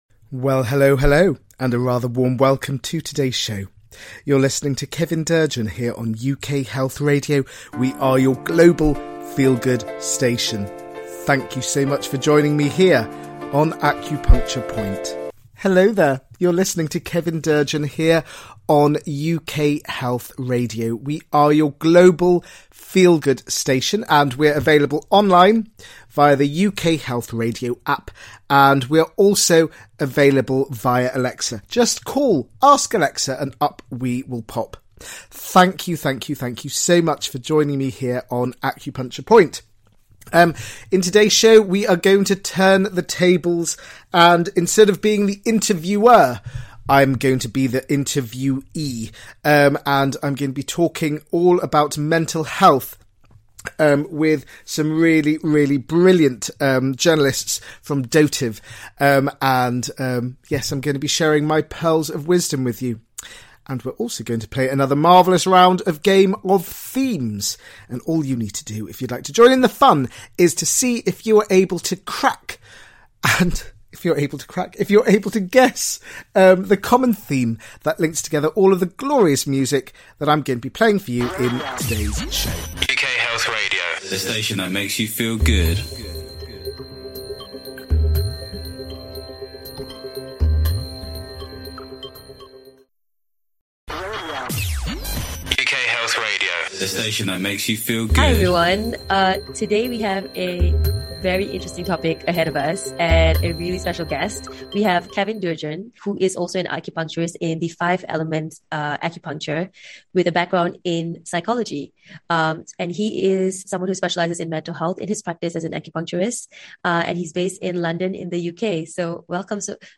He will also play some gorgeous music to uplift your soul and get your feet tapping with happiness.